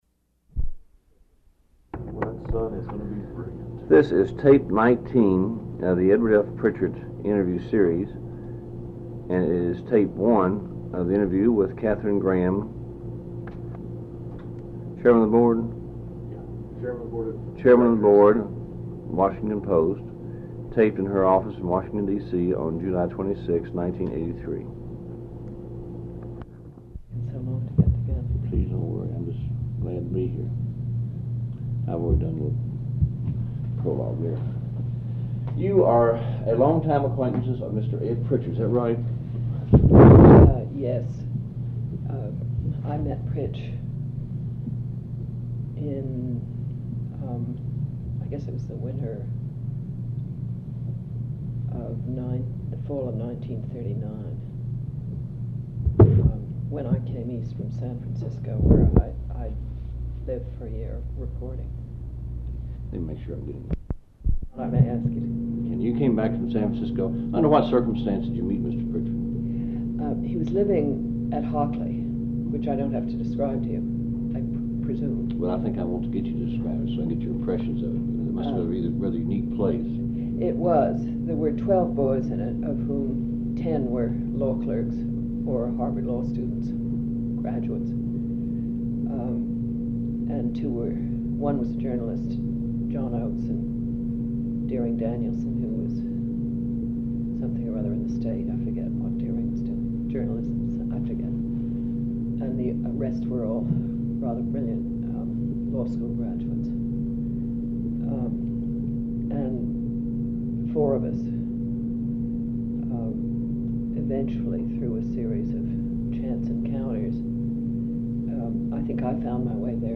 Oral History Interview with Katherine Graham